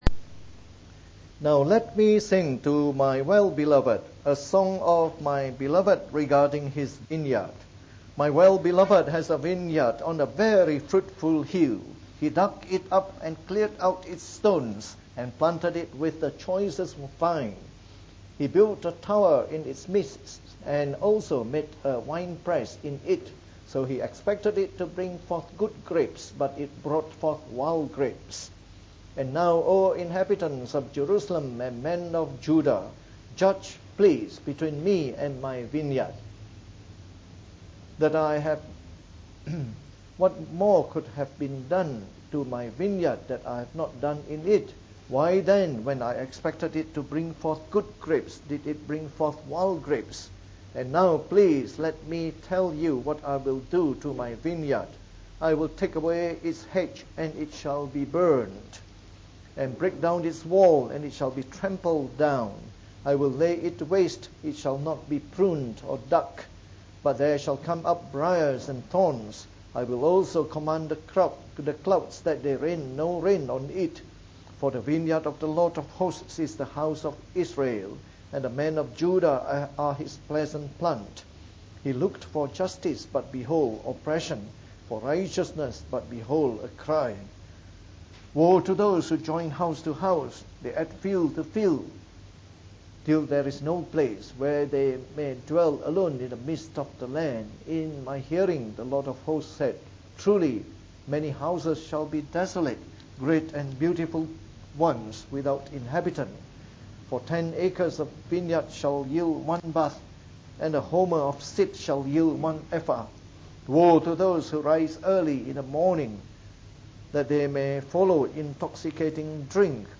From our new series on the book of Isaiah delivered in the Morning Service.